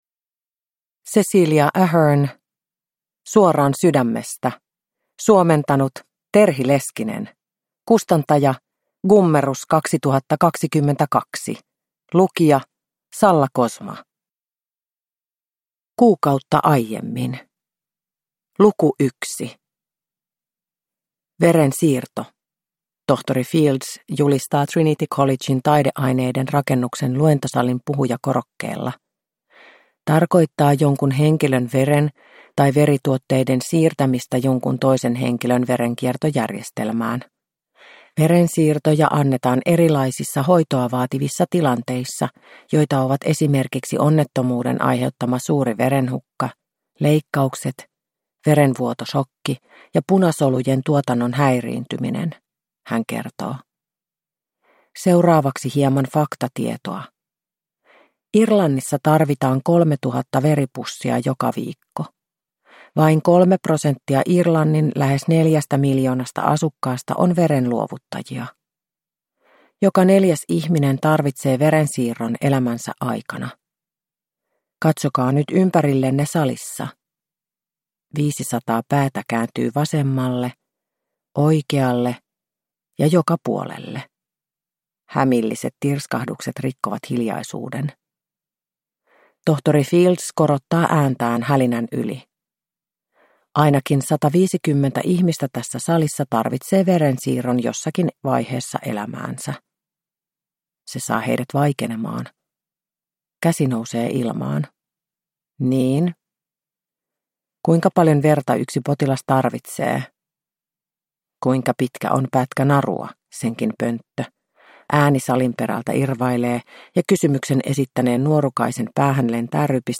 Suoraan sydämestä – Ljudbok – Laddas ner